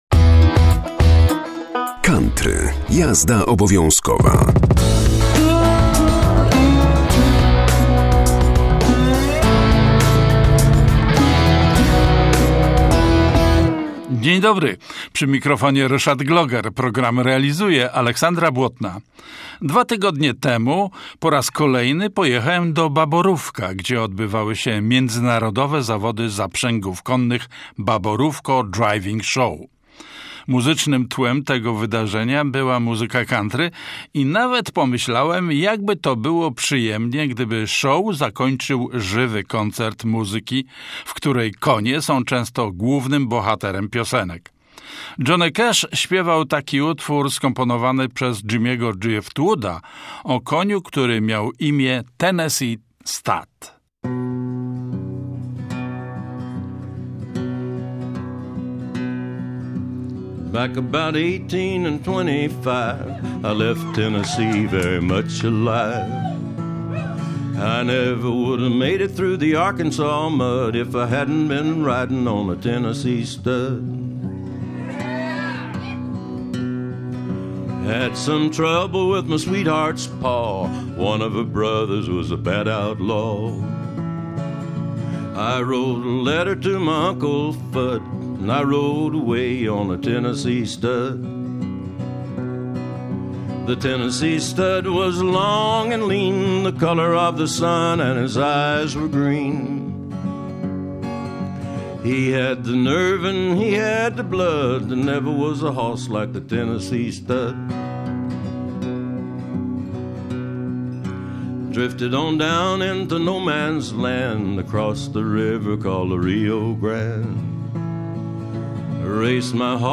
COUNTRY - JAZDA OBOWIĄZKOWA 12.10.2025